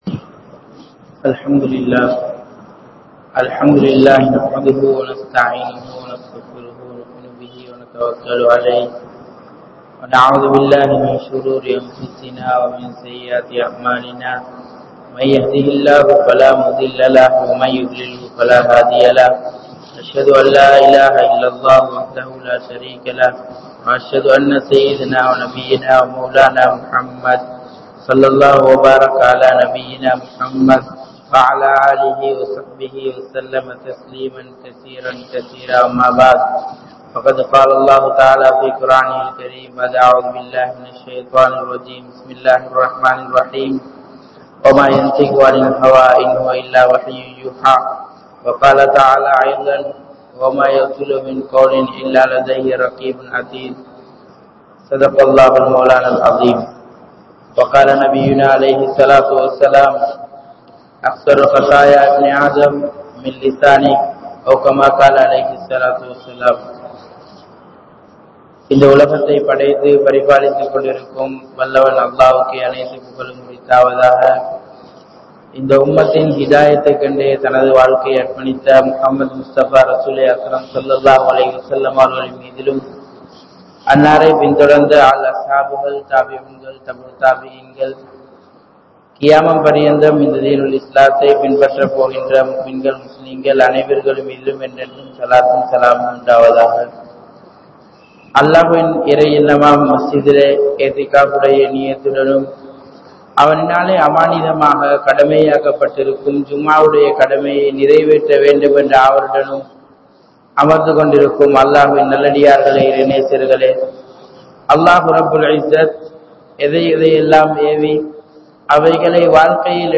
Naavai Paathuhaarungal (நாவை பாதுகாருங்கள்) | Audio Bayans | All Ceylon Muslim Youth Community | Addalaichenai